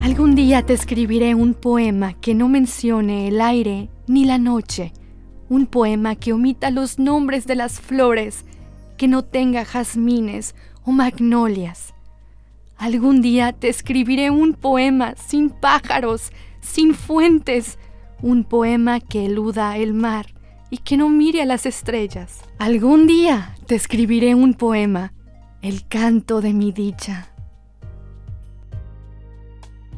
Spanish (Mexico) and English (USA) female speaker.
Sprechprobe: Sonstiges (Muttersprache):
poema.mp3